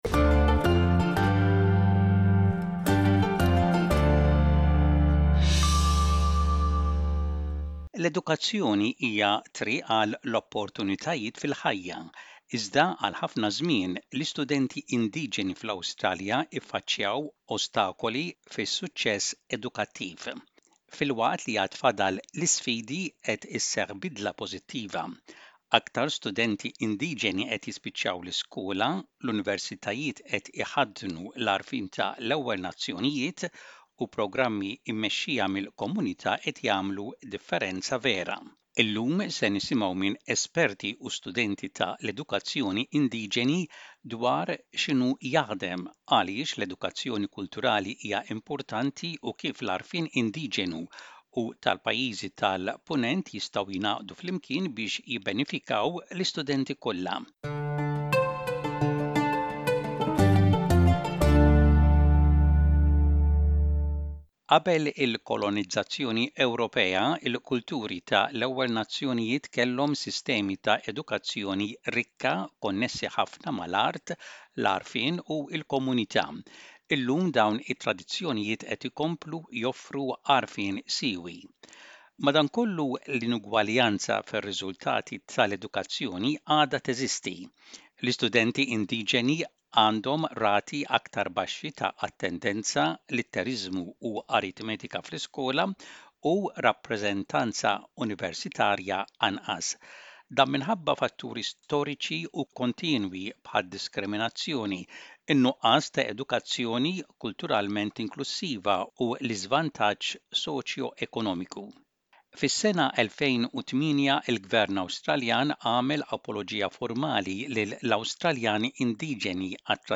Illum ser nisimgħu minn esperti u studenti tal-edukazzjoni Indiġeni dwar x’qed jaħdem, għaliex l-edukazzjoni kulturali hija importanti u kif l-għarfien Indiġenu u tal-Pajjiżi tal-Punent jistgħu jingħaqdu flimkien biex jibbenefikaw l-istudenti kollha.